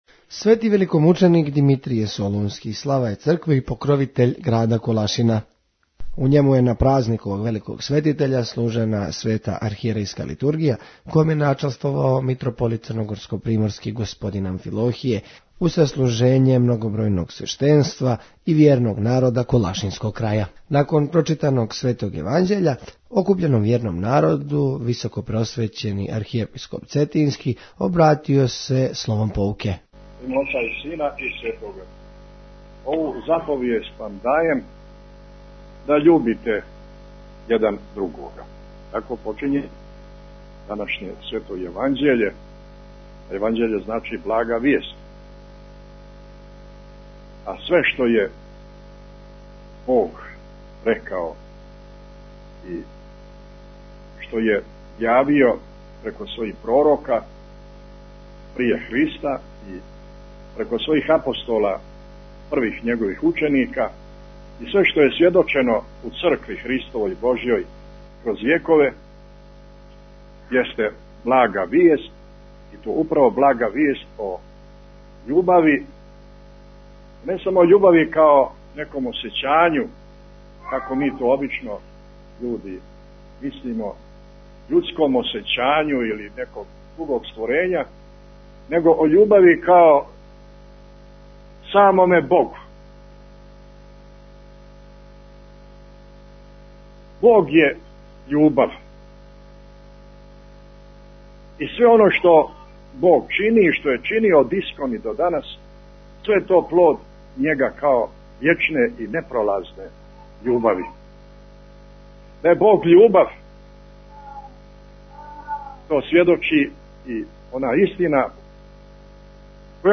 Свети великомученик Димитрије, наш велики заступник пред Господом Tagged: Бесједе